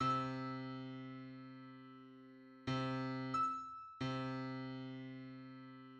Musically, in just intonation, the interval of a decade is precisely a just major twenty-fourth, or, in other words, three octaves and a just major third.
Decade_on_C.mid.mp3